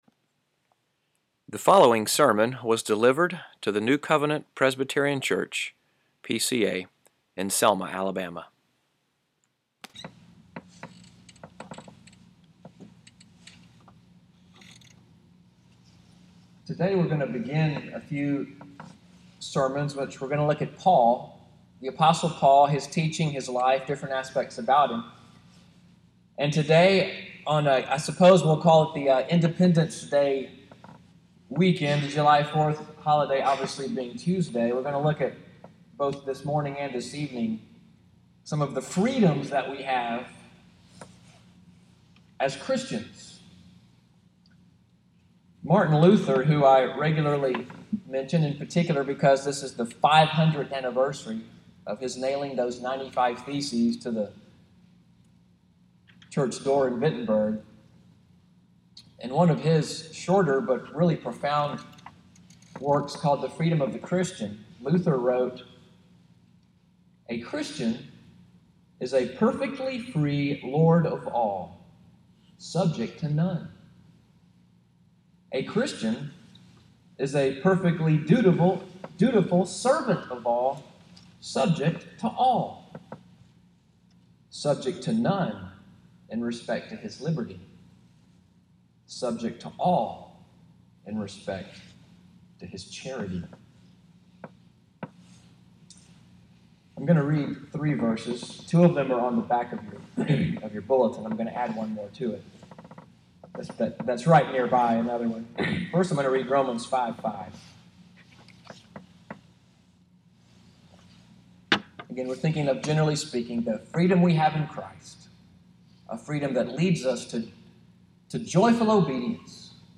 MORNING WORSHIP at NCPC, audio from today’s sermon, “Love and Freedom,” July 2, 2017